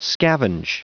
Prononciation du mot scavenge en anglais (fichier audio)
scavenge.wav